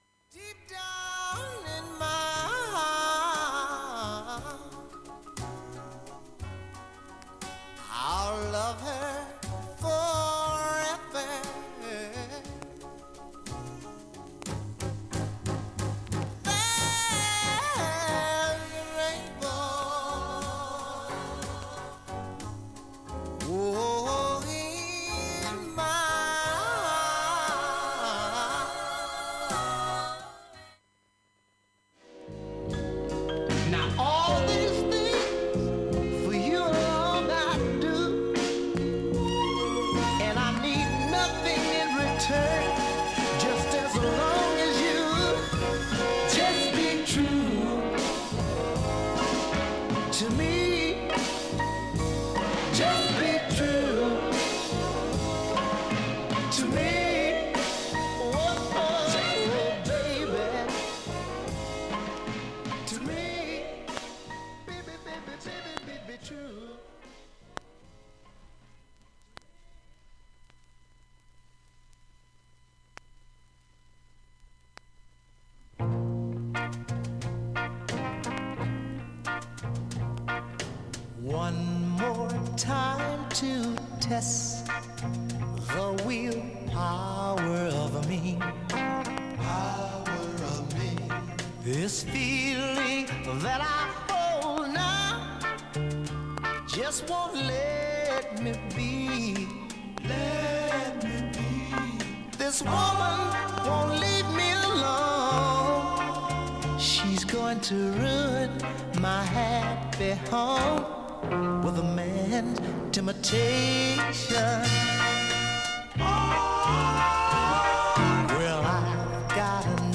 B-1途中に小さい点キズで、わずかなプツ出ます。
B-5は計８回わずかなプツ程度です。
音質目安にどうぞ ほかクリアな音質良好全曲試聴済み。
ＵＳＡ盤/MONO
シカゴノーザンソウル傑作